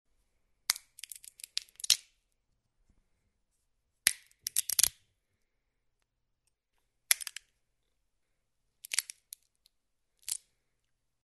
Звук разламывающегося грецкого ореха